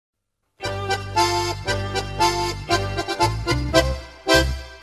纯音乐 - 一首欢快的音乐1.mp3